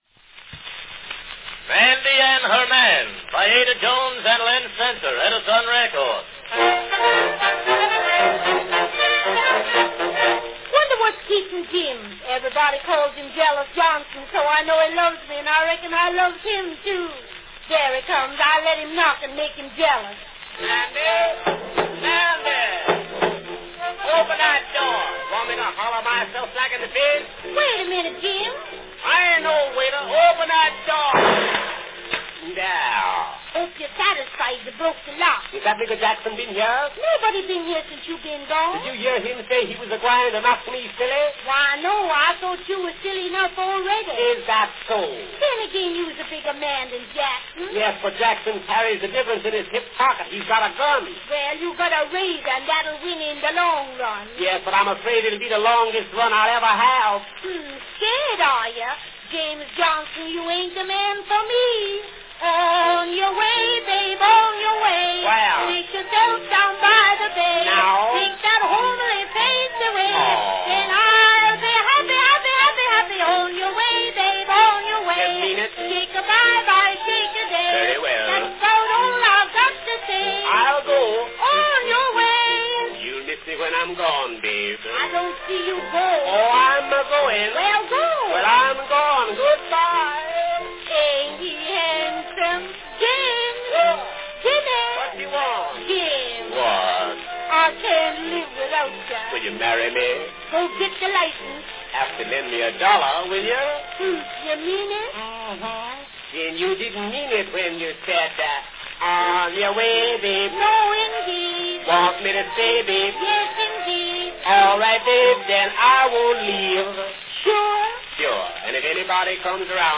A lively vaudeville sketch from 1906
Category Vaudeville sketch
with orchestra accompaniment